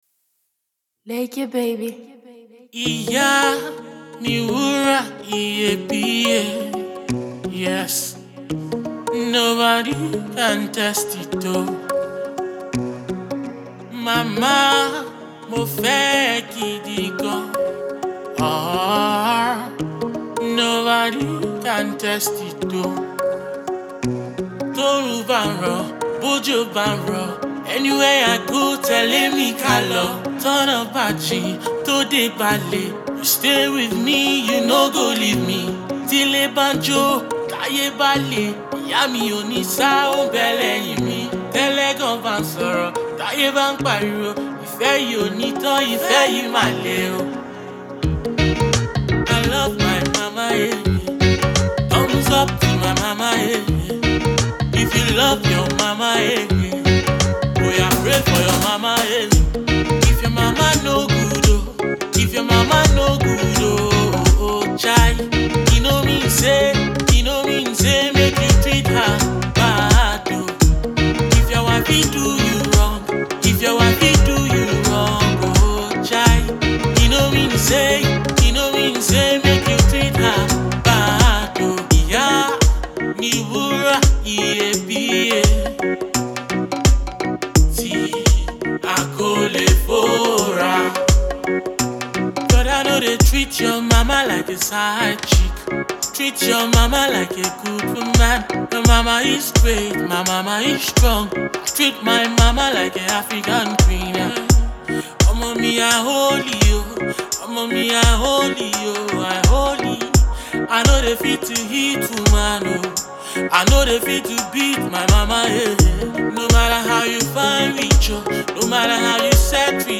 song of appreciation
crooner